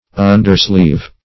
Search Result for " undersleeve" : The Collaborative International Dictionary of English v.0.48: Undersleeve \Un"der*sleeve`\, n. A sleeve of an under-garment; a sleeve worn under another, [1913 Webster] Underslung